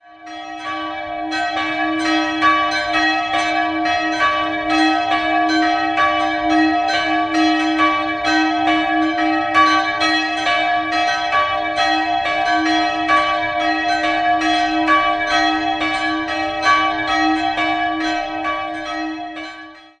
3-stimmiges Geläut: d''-e''-g'' (tief) Die große Glocke wurde 1700 von Johann Gordian Schelchshorn in Regensburg gegossen, die mittlere stammt von Perner (vermutlich von 1947) und über die kleine liegen derzeit keine näheren Angaben vor.